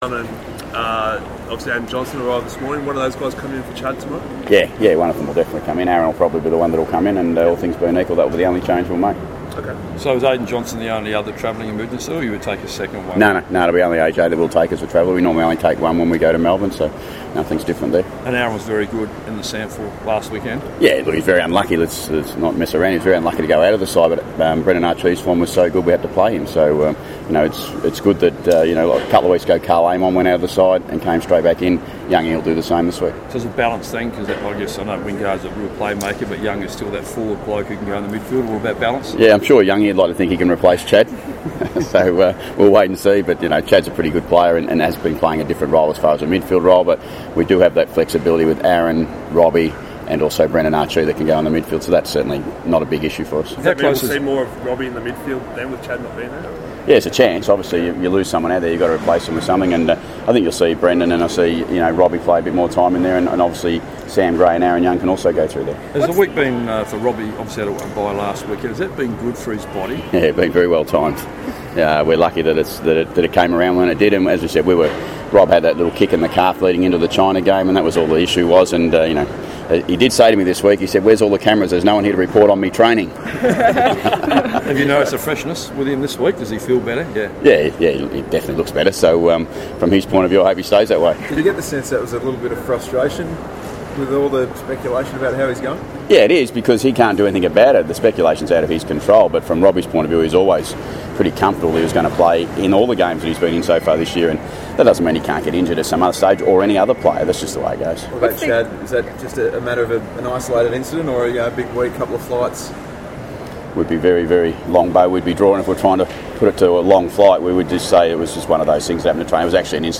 Ken Hinkley's airport press conference - Wednesday 24 May
Ken Hinkley speaks at the airport prior to his departure.